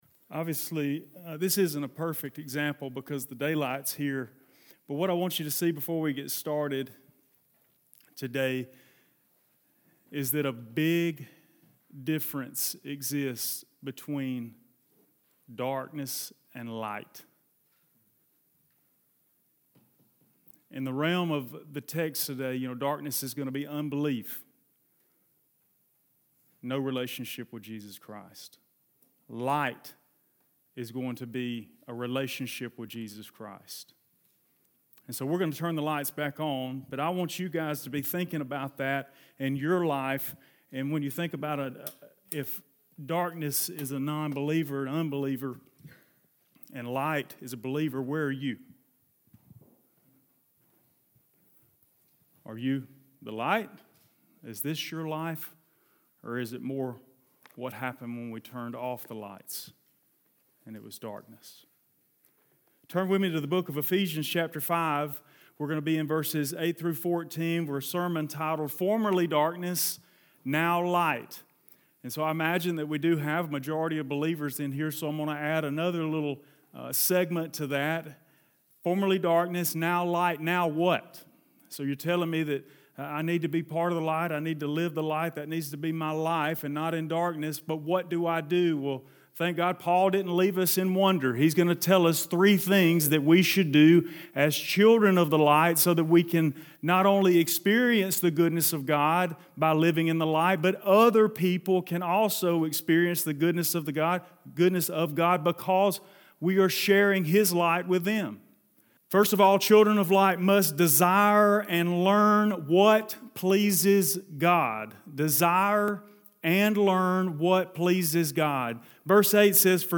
Formerly Darkness, Now Light | Ephesians 5:8-14 | Sunday Sermon